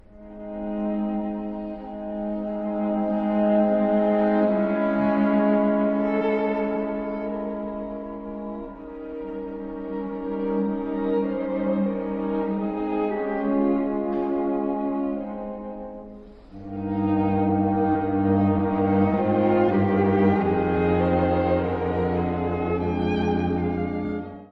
黄昏時のような哀愁を醸し出す緩徐楽章。
As-durというさらに深くなった調性が、聴き手を数多の想像にいざないます。
所々に現れる半音のうつろいも素敵。
にじむような雰囲気を見せてくれます。